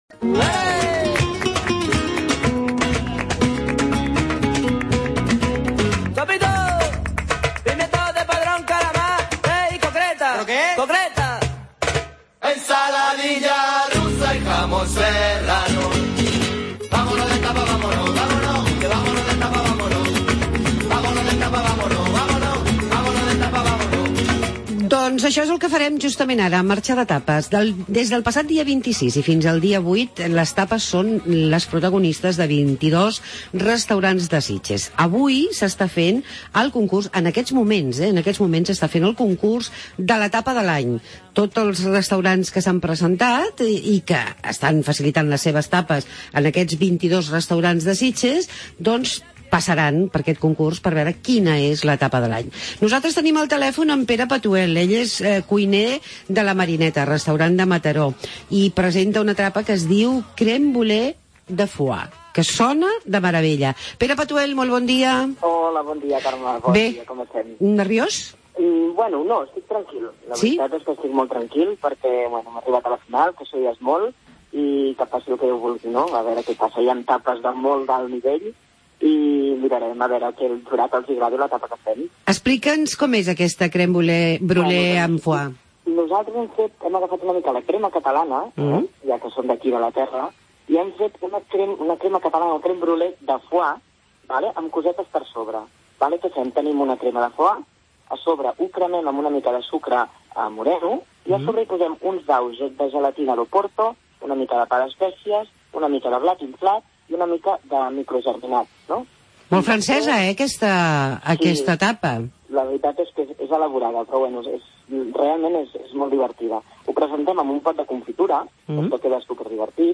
Hablamos con uno de los finalistas de la TAPA DE L'ANY